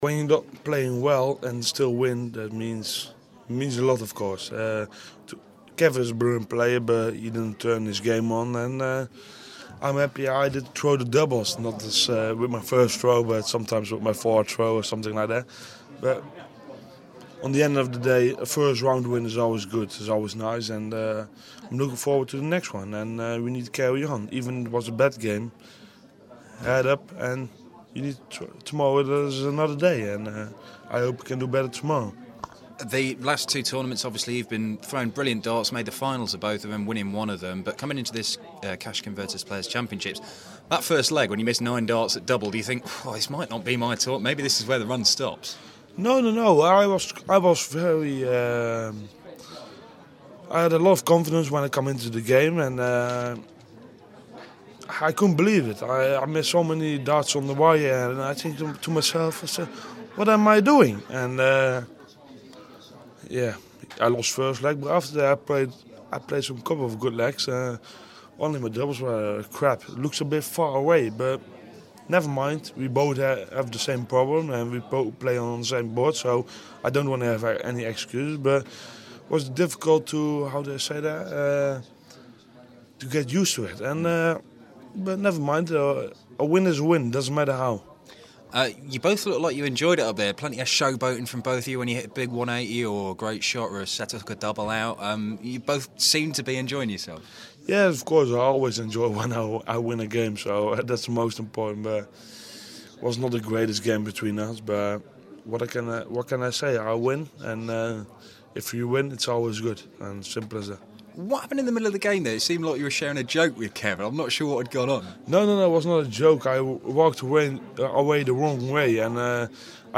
CCPC12 - van Gerwen Int (First round)